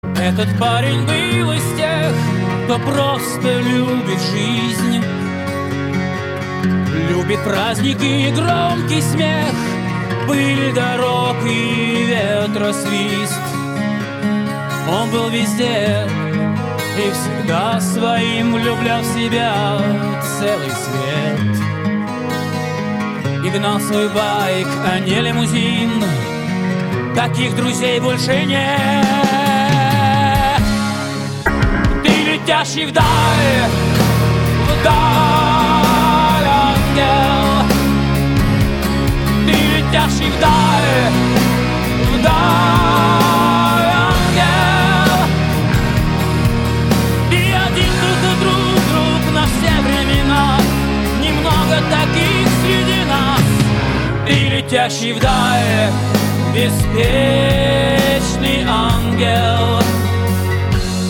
• Качество: 160, Stereo